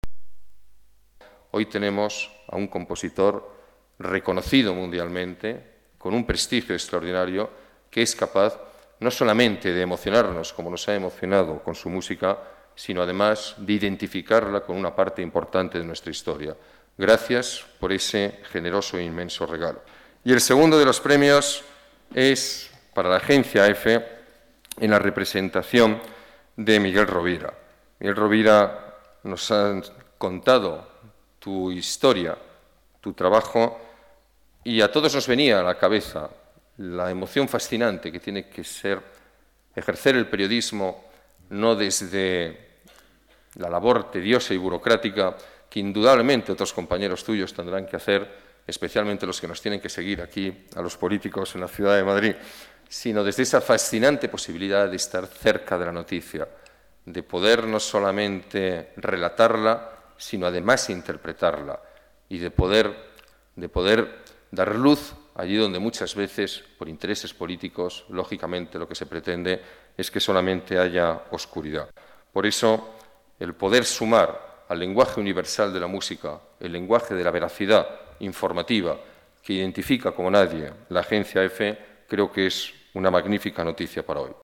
El alcalde asiste a la entrega de los premios de Casa Asia
Nueva ventana:Declaraciones del alcalde, Alberto Ruiz-Gallardón